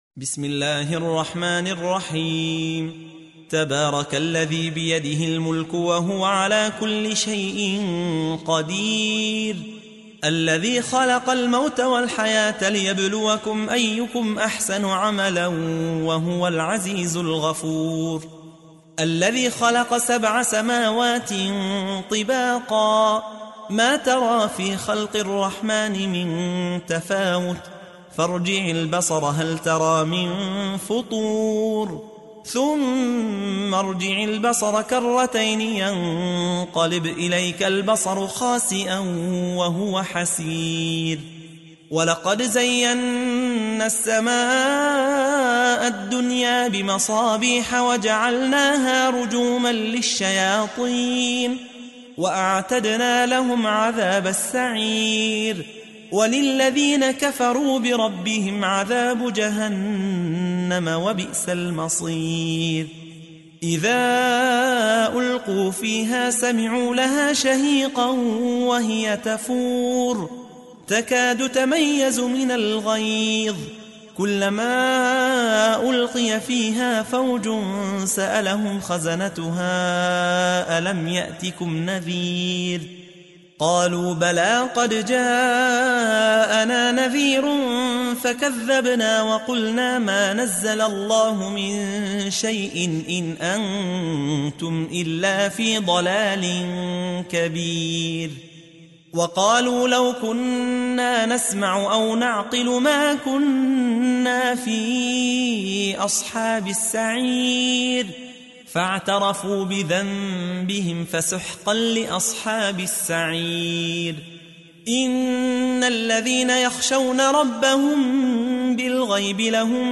تحميل : 67. سورة الملك / القارئ يحيى حوا / القرآن الكريم / موقع يا حسين